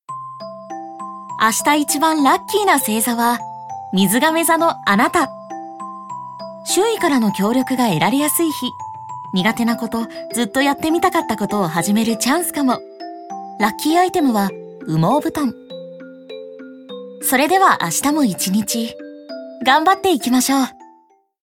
ボイスサンプル
(優しく語り掛ける夜ver.)